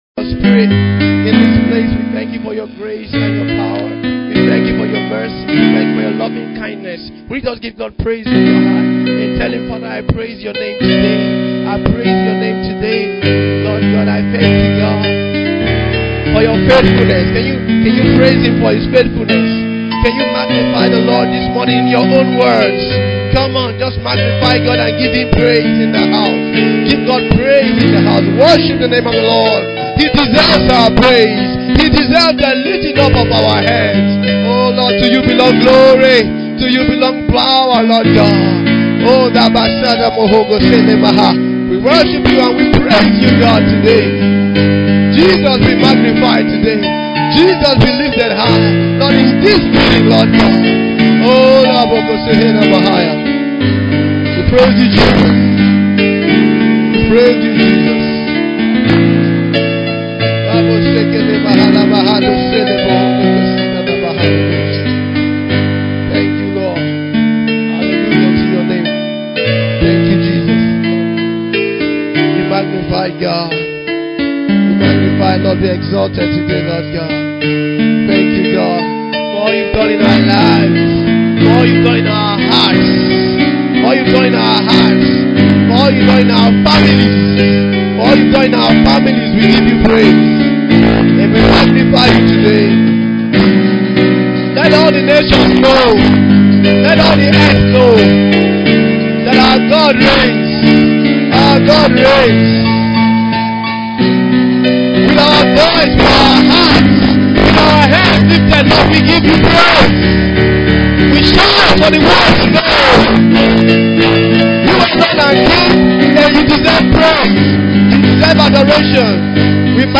This sermon has ignited a deeper hunger for God in the hearts of many.